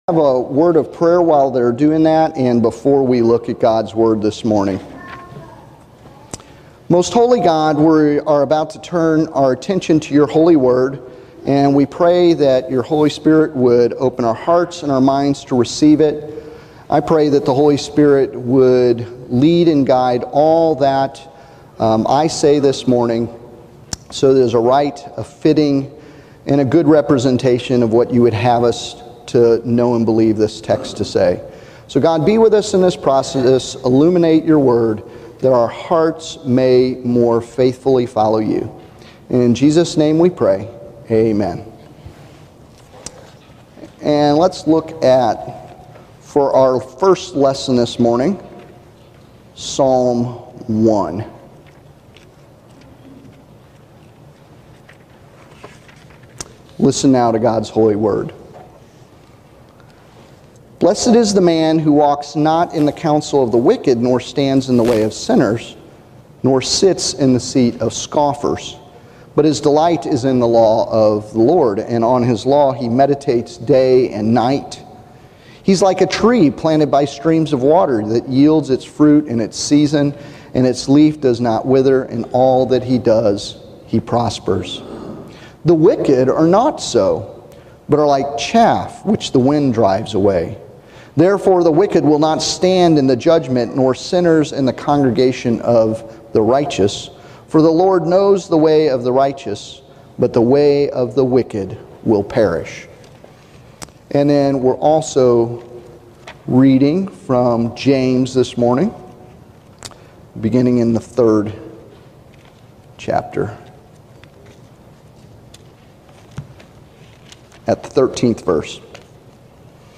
Sermon-Abiding-in-Gods-Wisdom-James-313-48.mp3